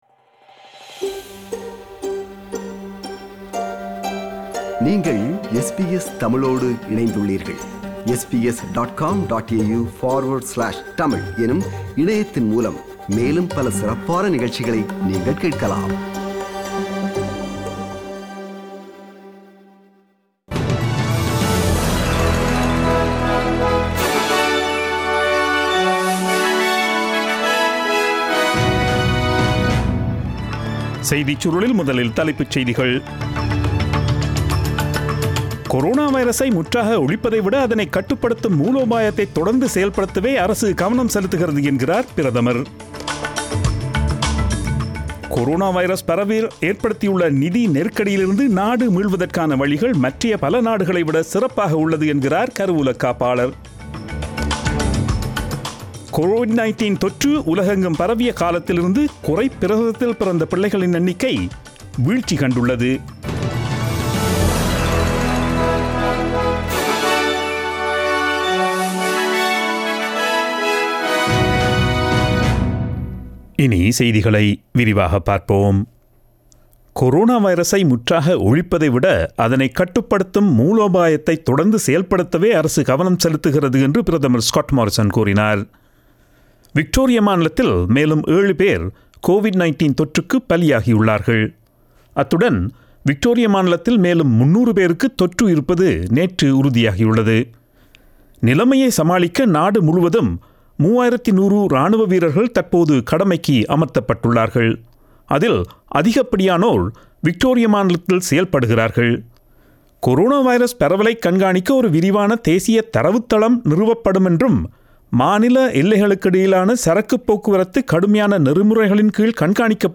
Australian news bulletin aired on Friday 24 July 2020 at 8pm.